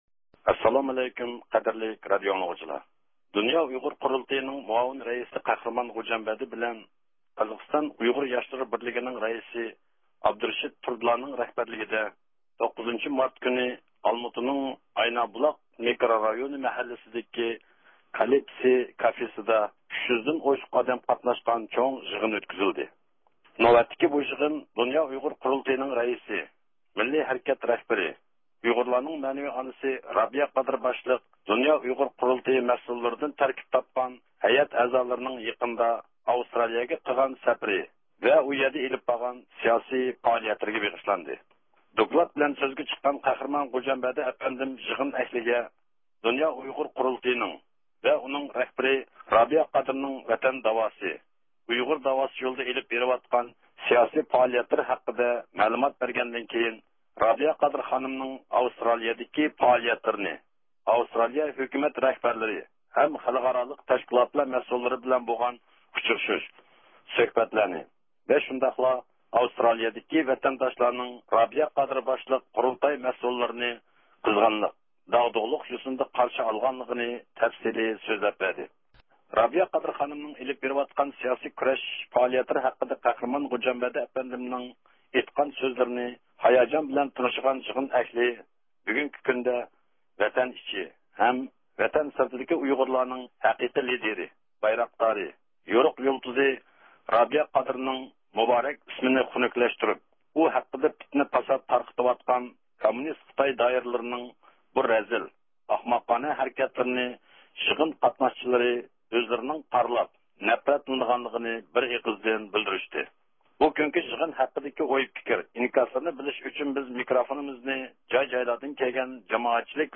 تەپسىلىي خەۋىرىنى